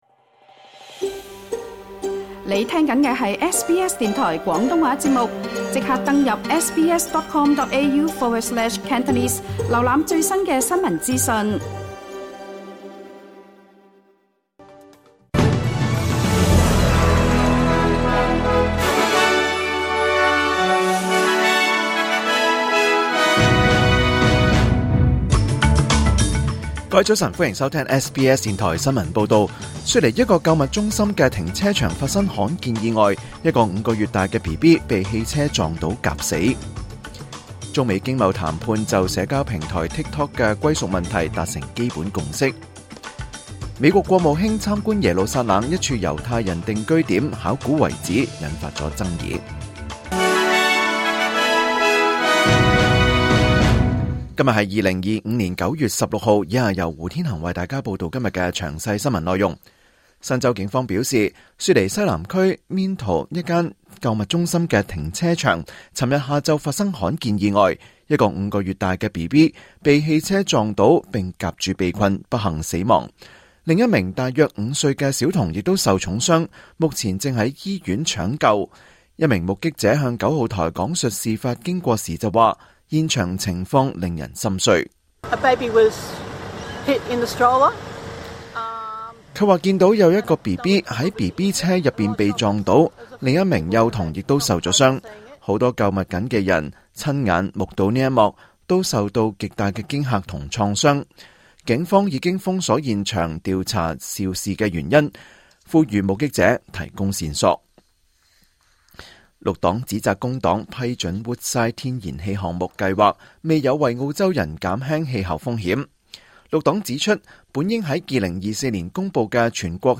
2025年9月16日SBS廣東話節目九點半新聞報道。